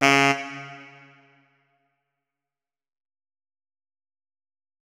saxophone